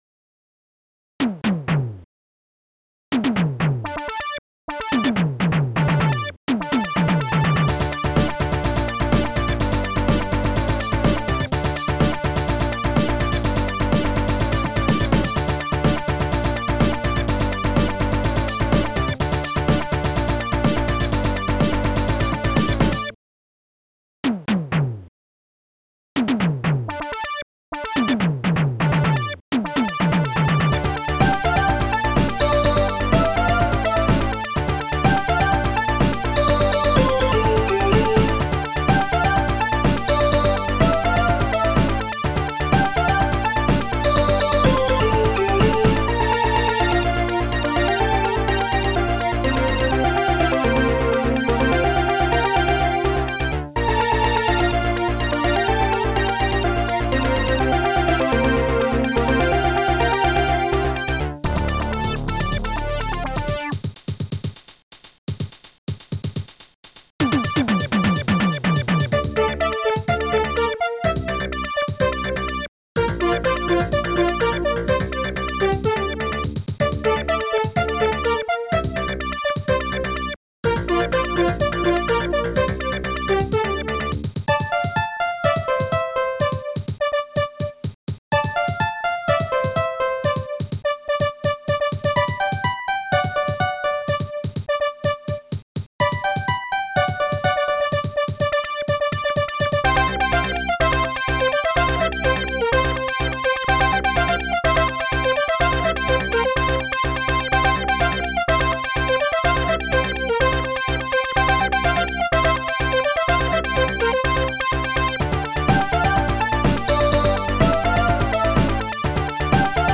Protracker Module
bassdrum2 korgfilter electom tunebass strings1 ringpiano hallbrass hihat2 korgdoi snare1 touch steinway squares funkbass closehihat